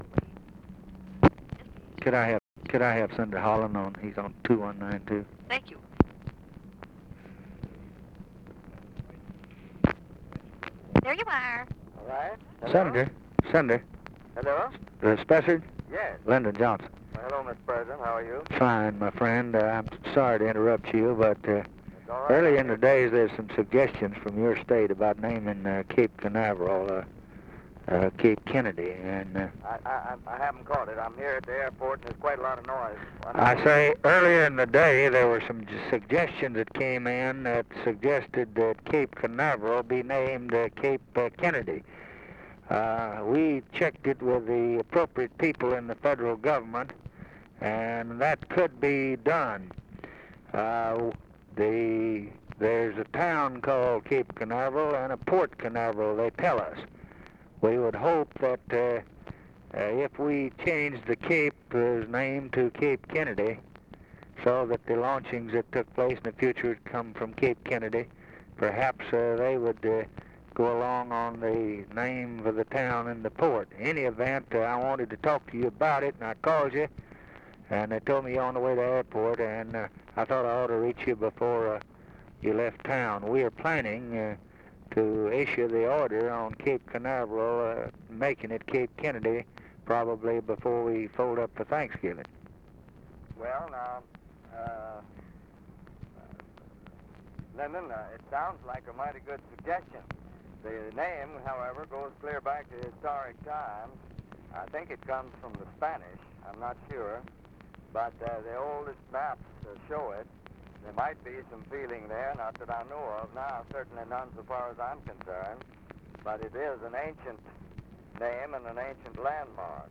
Conversation with SPESSARD HOLLAND, November 27, 1963
Secret White House Tapes